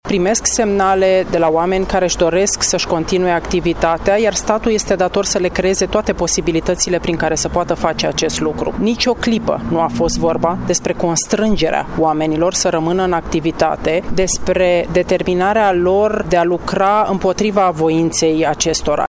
Ministrul Muncii, Violeta Alexandru: